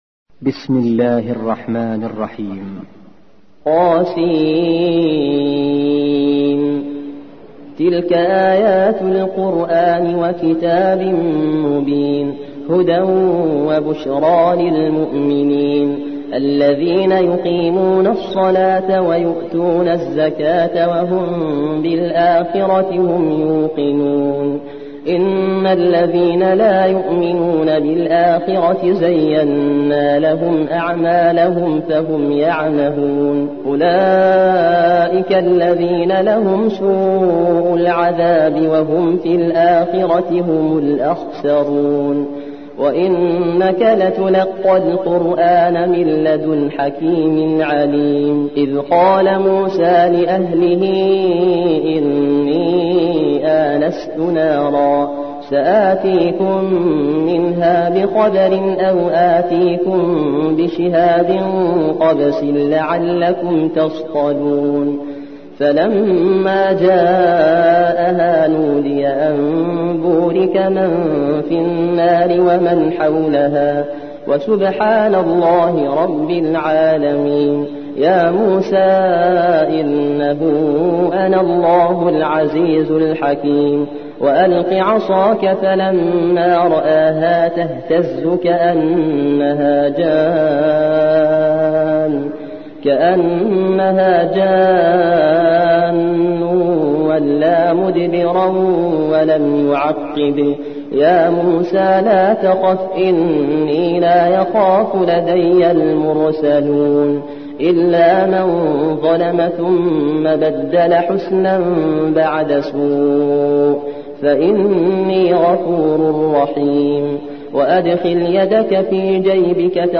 27. سورة النمل / القارئ